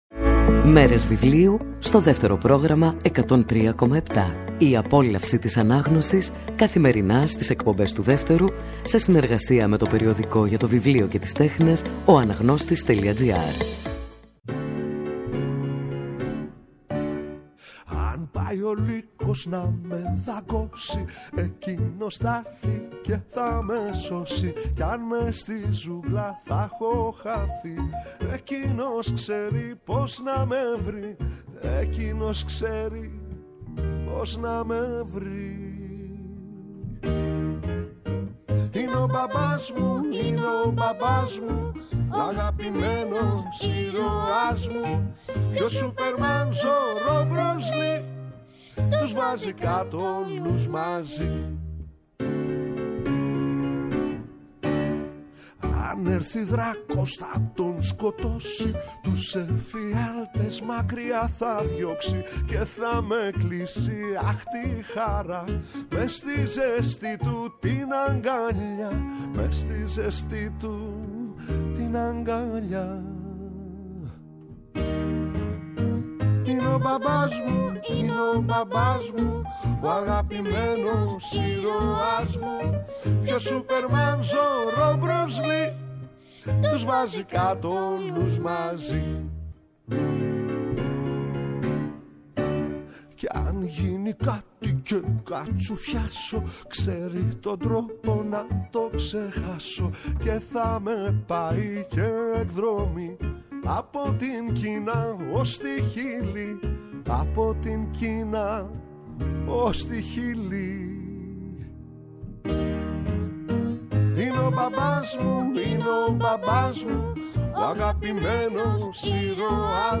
Το Σάββατο 19 Δεκεμβρίου, στις 09:00, ο συγγραφέας Μάκης Τσίτας μιλάει για το συγγραφικό του τάνυσμα ανάμεσα στον κόσμο των ενηλίκων και των παιδιών,